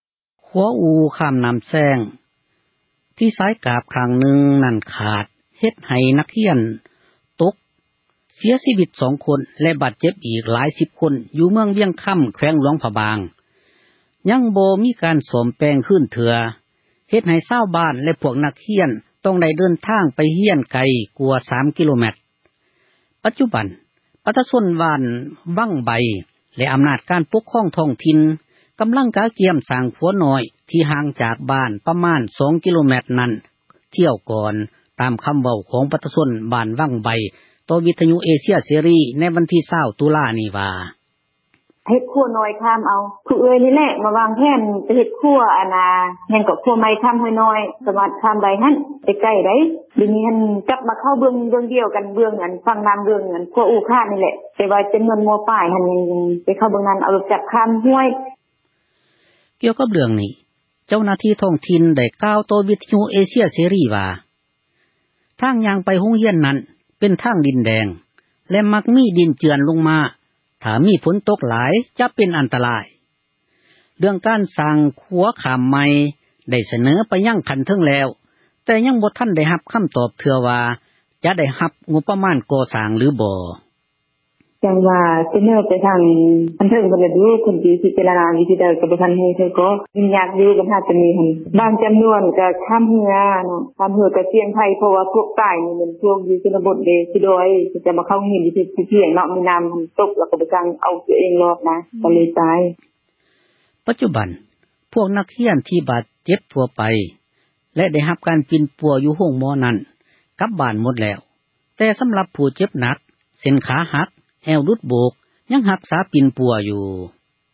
ຂົວອູ່ຂ້າມນໍ້າແຊງ ທີ່ສາຍກ້າບ ຂາດ ຂ້າງນຶ່ງ ເຮັດໃຫ້ນັກຮຽນ ເສັຽຊີວິດ 2 ຄົນ ແລະ ບາດເຈັບອີກ ຫຼາຍສິບຄົນ ຢູ່ເມືອງວຽງຄໍາ ແຂວງ ຫຼວງພຣະບາງ ຍັງບໍ່ມີ ການ ສ້ອມແປງ ຄືນເທື່ຶອ ເຮັດໃຫ້ຊາວບ້ານ ແລະ ພວກນັກຮຽນ ຕ້ອງໄດ້ ເດີນທາງໄປ ຮຽນໄກກວ່າ ແຕ່ກ່ອນ 3 ກິໂລແມັດ. ປັດຈຸບັນ ຊາວບ້ານວັງໃບ ແລະ ອໍານາດ ການປົກຄອງ ທ້ອງຖິ່ນ ກໍາລັງກຽມ ສ້າງຂົວນ້ອຍ ທີ່ຫ່າງຈາກ ບ້ານປະມານ 2 ກິໂລແມັດ ນັ້ນທຽວກ່ອນ. ຕາມຄໍາເວົ້າ ຂອງ ປະຊາຊົນ ບ້ານວັງໃບ ຕໍ່ RFA ໃນວັນທີ 20 ຕຸລາ ນີ້ວ່າ: